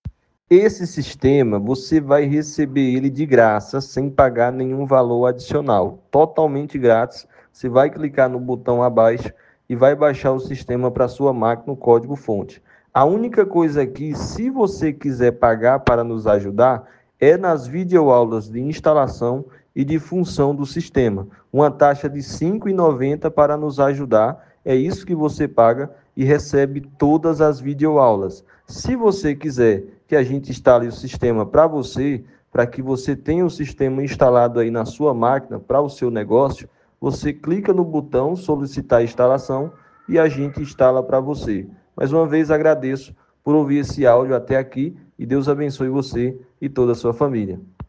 ATENÇÃO IMPERDÍVEL OUÇA O ÁUDIO ANTES DE BAIXAR O SEU PRODUTO GRÁTIS Mensagem do Programador O seu navegador não suporta o elemento de áudio. NÃO CONSEGUIU OUVI CLIQUE AQUI Sistema de WhatsApp Versão Gratuita Completa BAIXAR SISTEMA GRÁTIS Vídeo Aulas & Tutoriais Aprenda a instalar e a usar as funções BAIXAR VÍDEOS DE INSTALAÇÃO Precisa de Ajuda?